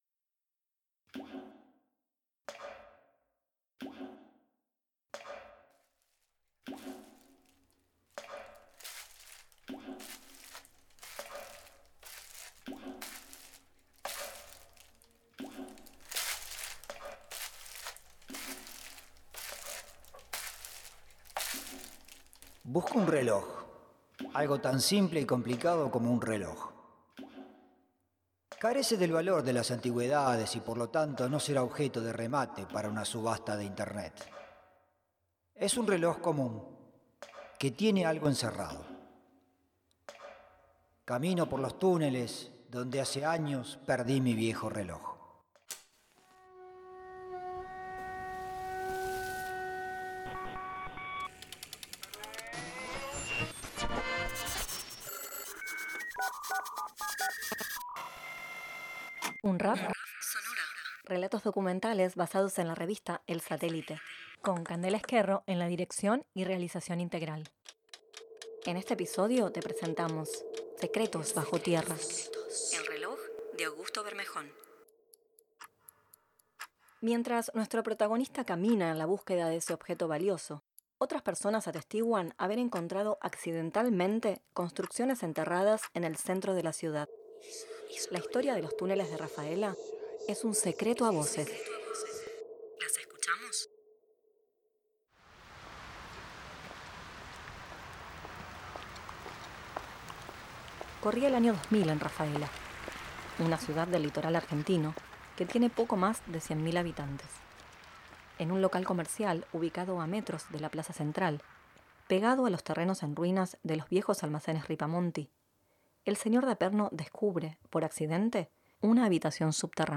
La propuesta del presente trabajo final integrador es diseñar y producir narrativas sonoras del género docudrama a partir de los relatos escritos de El Satélite (ES), revista de historia de la ciudad de Rafaela.